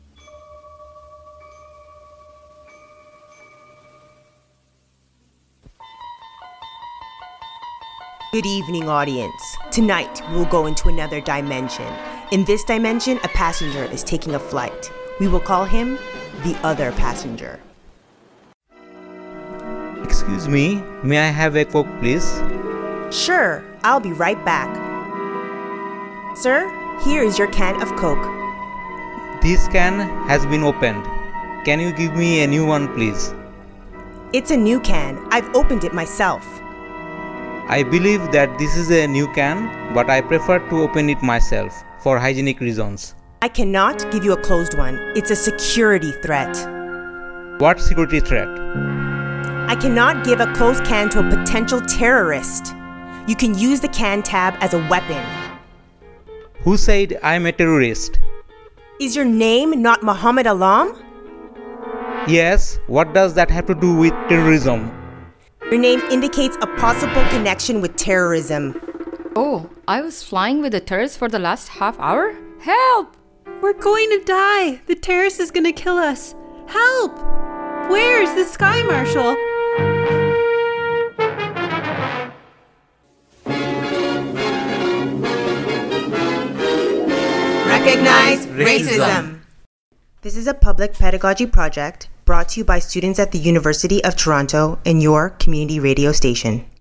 A radio drama / public service announcement about racial profiling by an airline
Type: PSA
128kbps Stereo